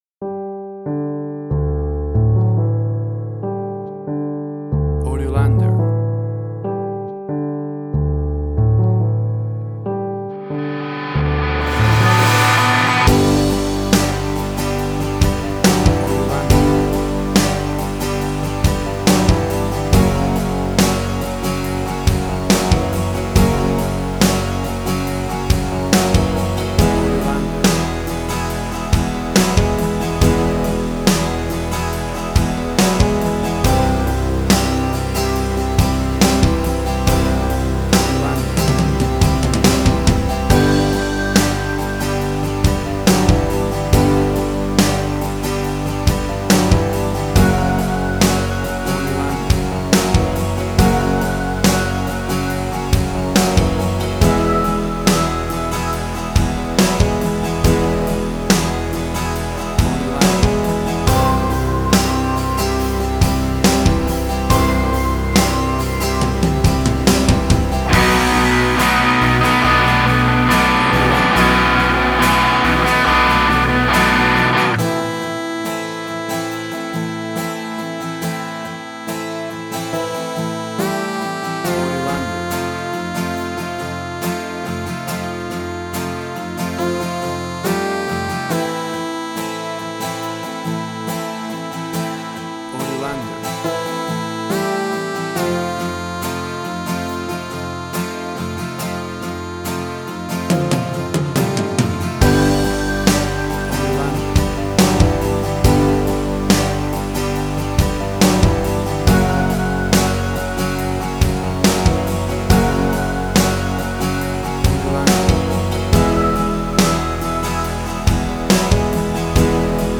Alternative Songs with vocals.
Tempo (BPM): 70